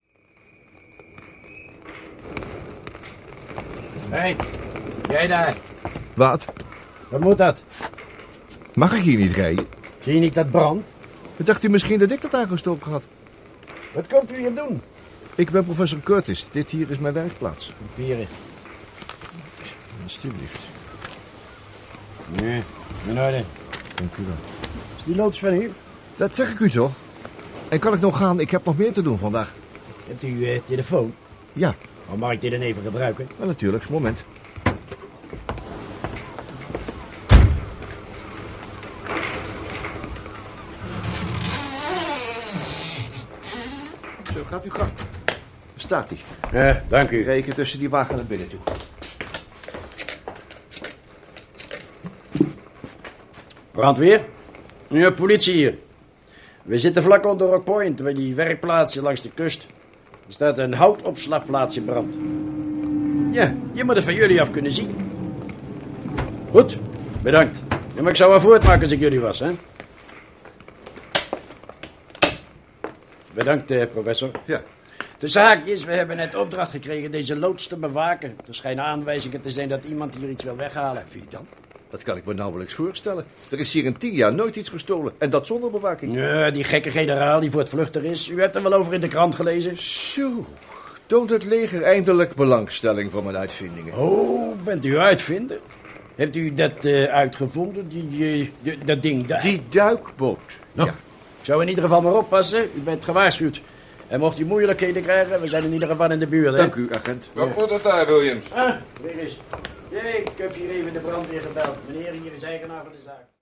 Rol(len) in de hoorspelreeks:
– De tunnel der duisternis – Wacht
– Prometheus XIII – agent